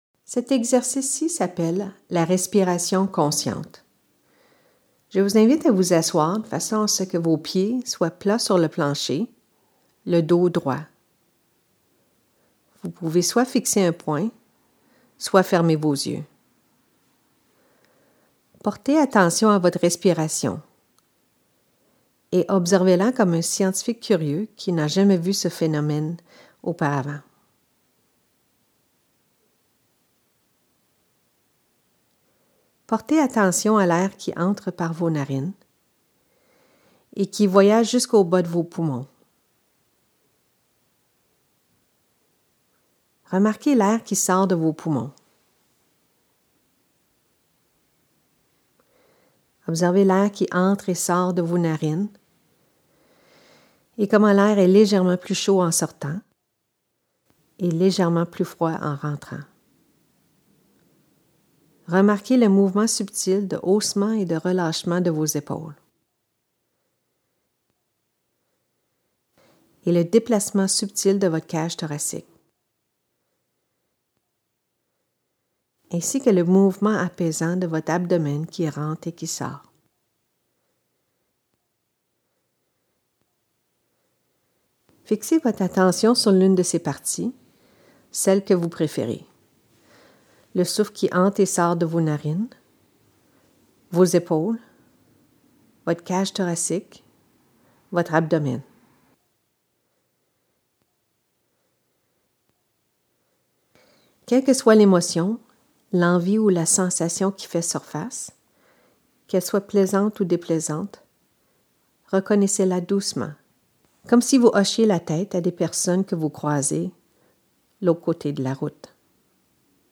Il s'agit d'un parmi plusieurs exercices audio basés sur des concepts de la Thérapie d'acceptation et d'engagement ("ACT", en anglais).